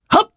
Theo-Vox_Attack1_jp.wav